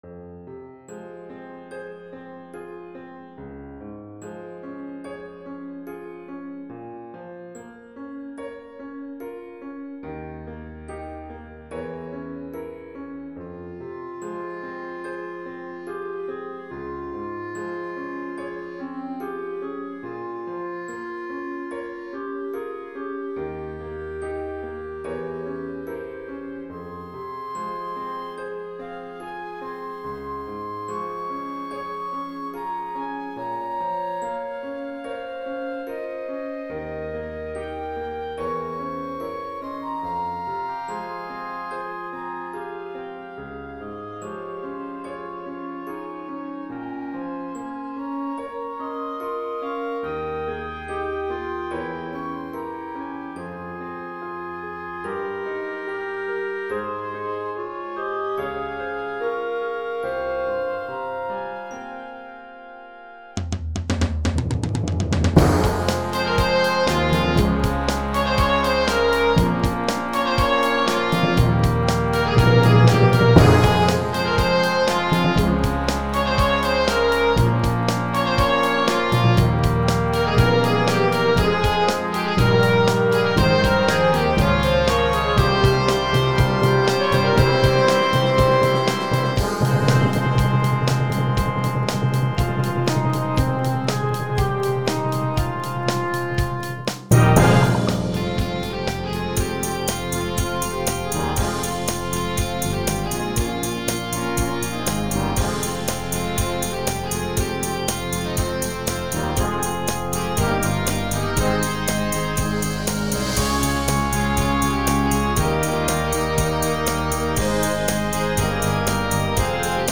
Jag gör produktionen både för symfoni- och blåsorkester.
Winds Score Preview